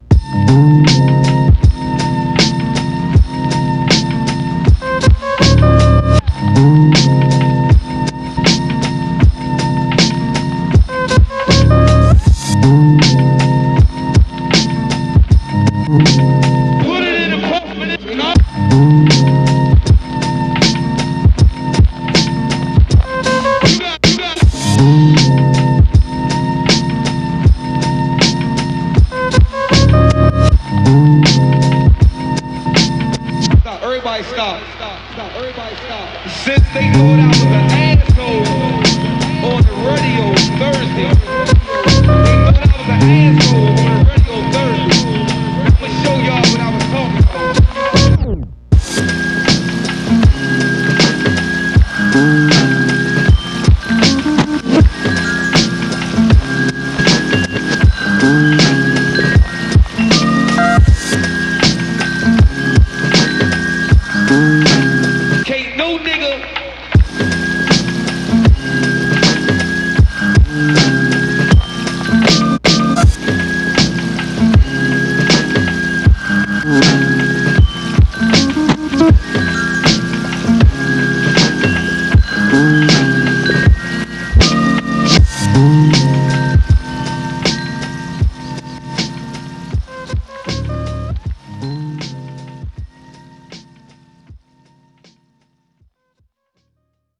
In which our heroes make a beat using the same source material.
Combine the two tracks below in any way you see fit.